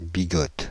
Ääntäminen
Ääntäminen Paris: IPA: [bi.gɔt] Tuntematon aksentti: IPA: /bi.ɡɔt/ Haettu sana löytyi näillä lähdekielillä: ranska Käännöksiä ei löytynyt valitulle kohdekielelle.